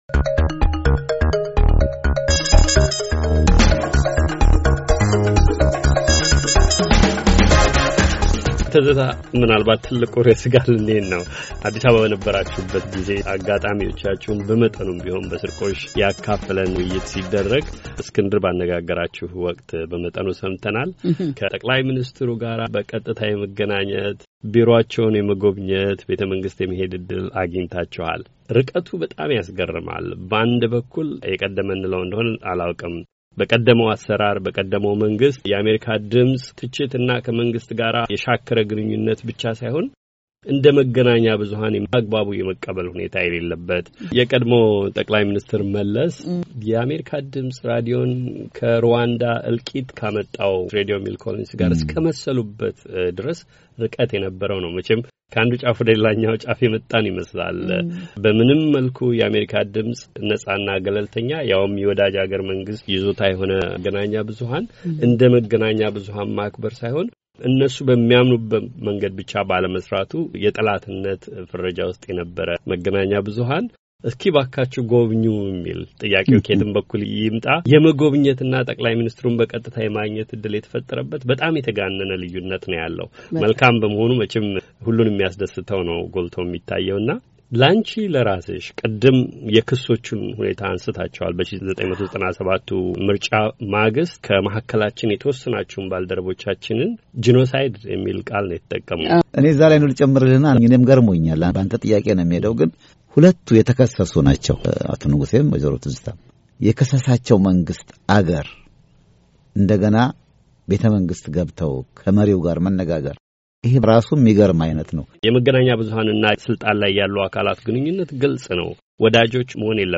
የጋዜጠኞች የጠረጴዛ ዙሪያ ውይይት፡- ትኩስ የፖለቲካ ዓየር .. ተስፋና ፈተናዎች .. ክፍል ሁለት